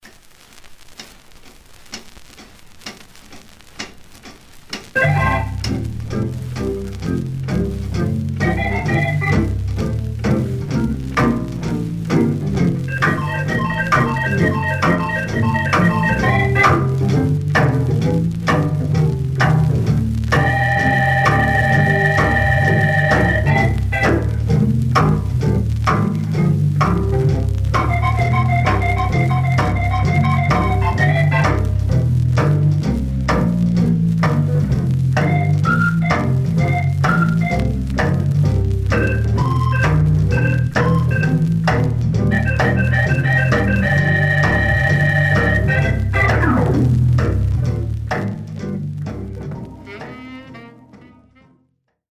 Stereo/mono Mono
R&B Instrumental